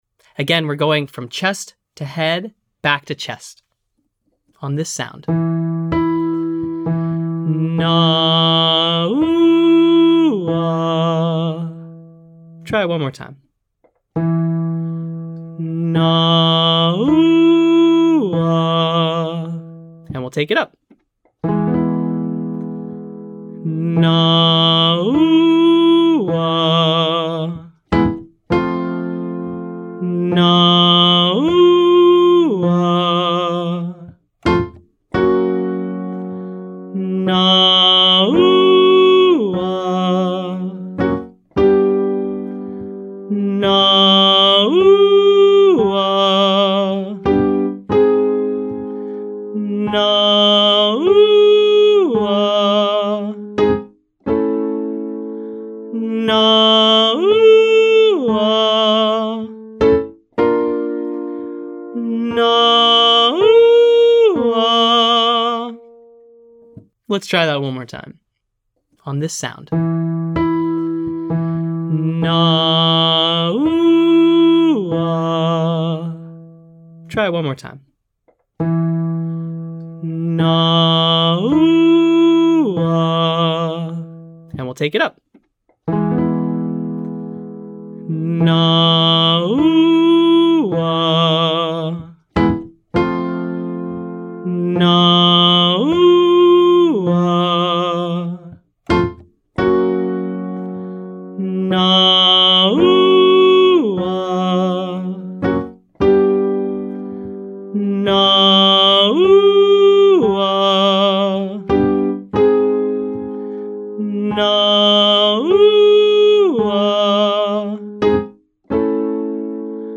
From: Daily Register Blending for Low Voices
Exercise: Octave jump Nah oo-ah 181  E
Again, we’re going chest to head back to chest.